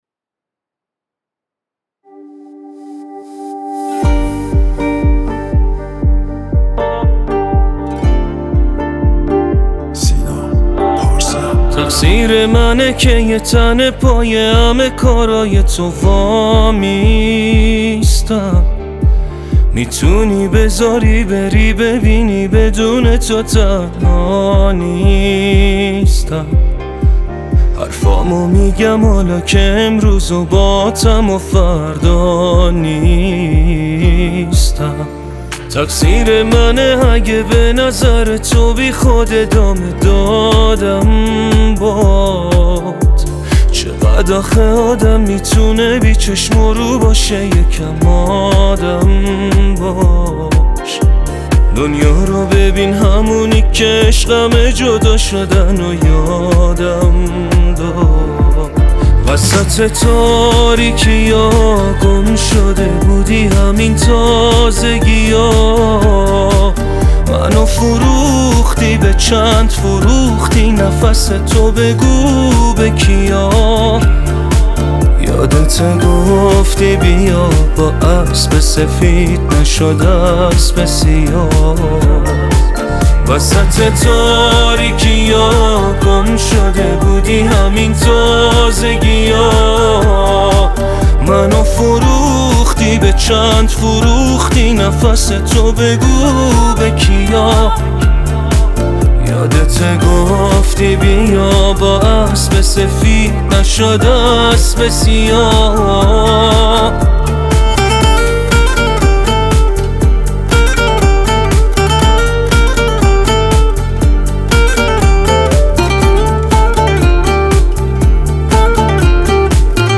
پاپ دیس لاو